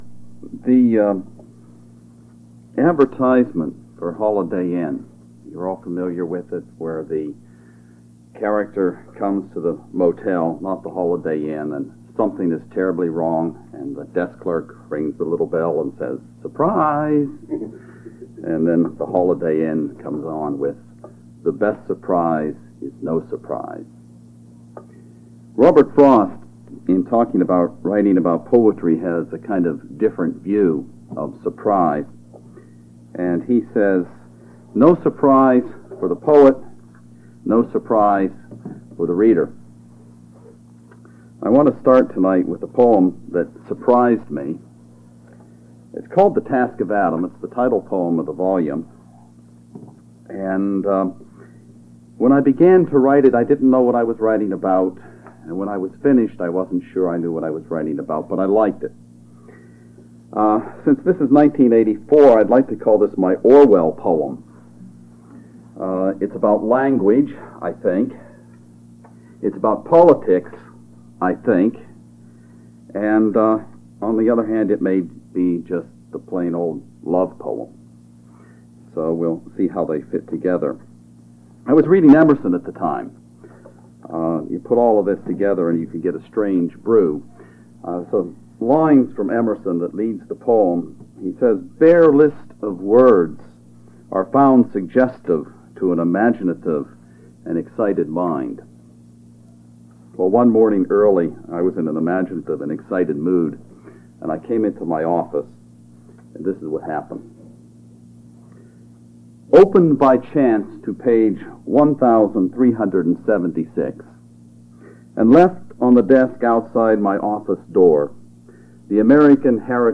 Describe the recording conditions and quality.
Chapel, "Readings in Poetry,"